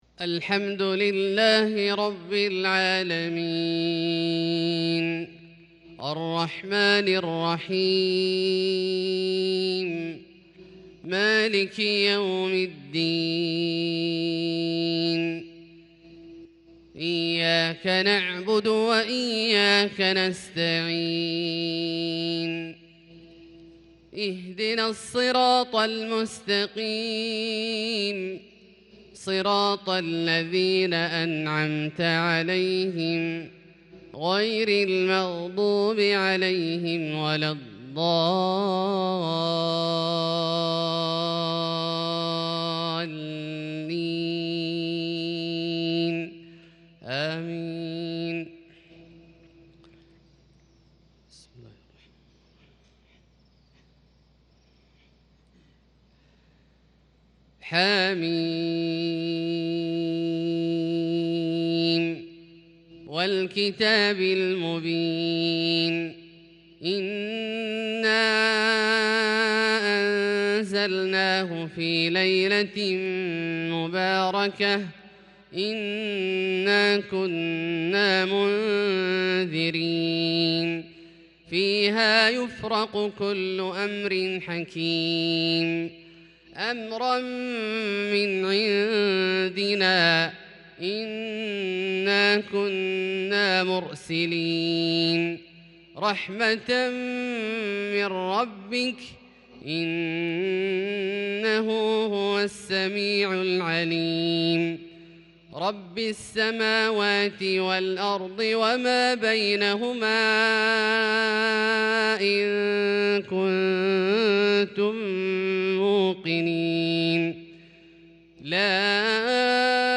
صلاة الفجر للقارئ عبدالله الجهني 25 جمادي الأول 1442 هـ
تِلَاوَات الْحَرَمَيْن .